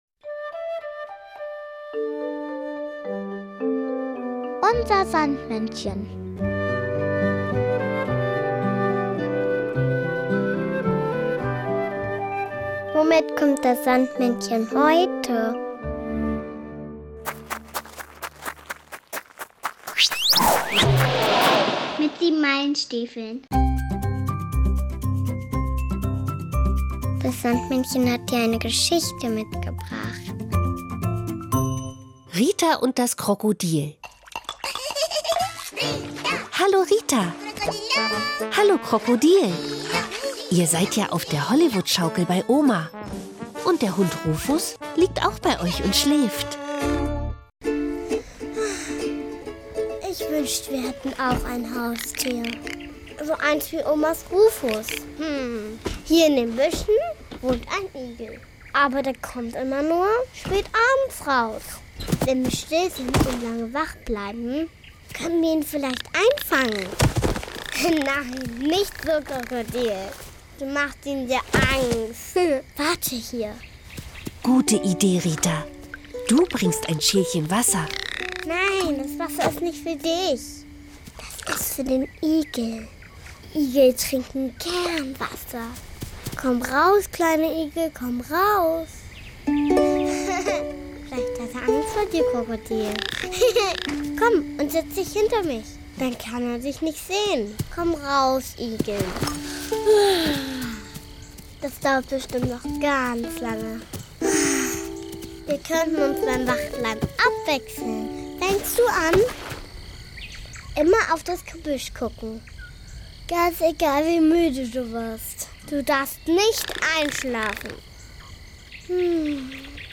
Unser Sandmännchen: Geschichten und Lieder 40 ~ Unser Sandmännchen Podcast